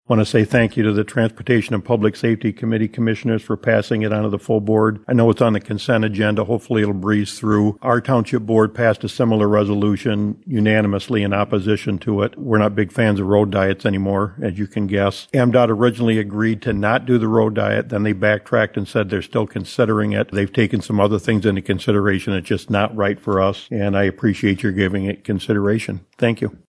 Blackman Township Clerk David Elwell spoke at Tuesday’s Jackson County Commissioners Meeting in support of opposing the road diet.